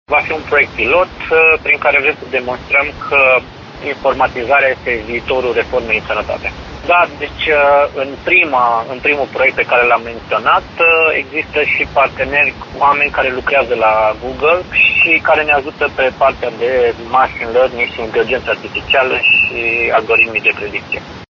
Şeful DSP Arad, Horea Timiş, a declarat că totul a început de la o conversaţie cu un arădean care lucrează la Google, iar DSP Arad a început să scrie proiectul de finanţare.